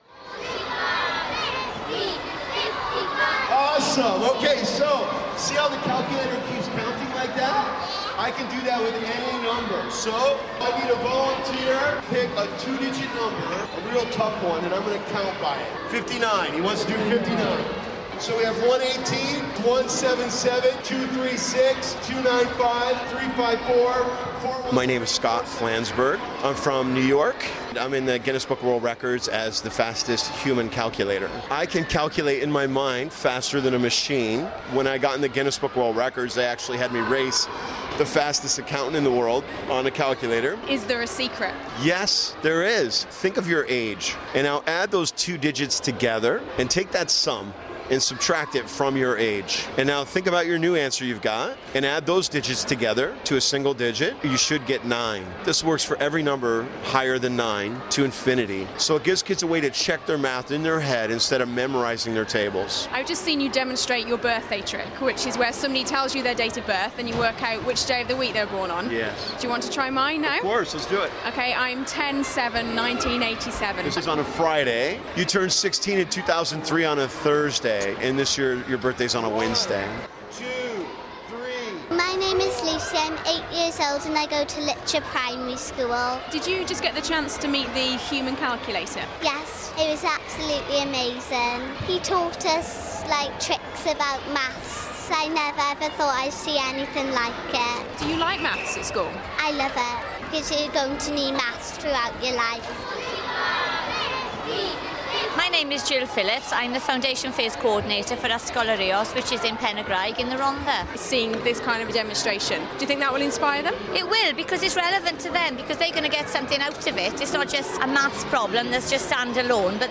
(Broadcast on BBC Radio Wales, February 2013).